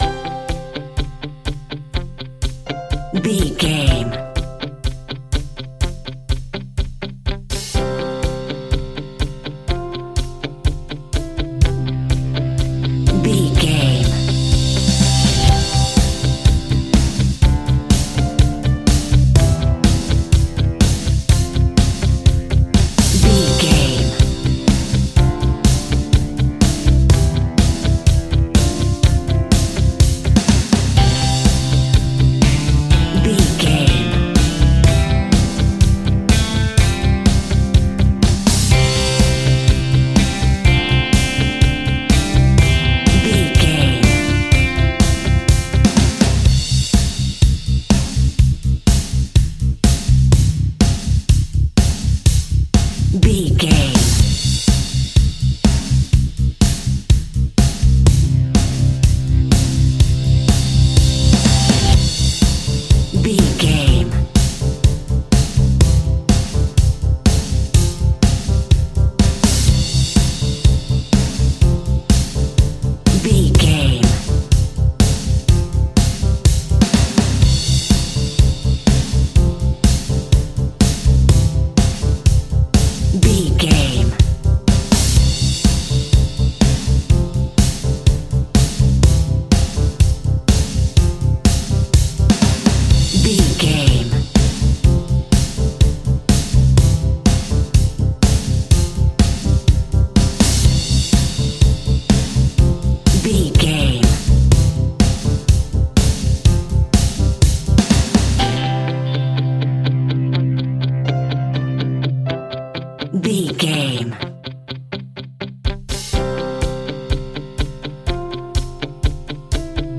Epic / Action
Fast paced
Uplifting
Ionian/Major
indie pop
fun
energetic
cheesy
instrumentals
guitars
bass
drums
piano
organ